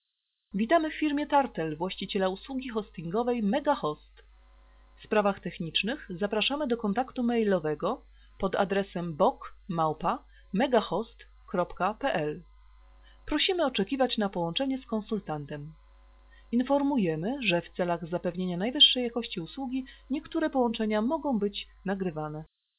Samples of recordings of HaloNet speakers below (wave files):
woman's voice 1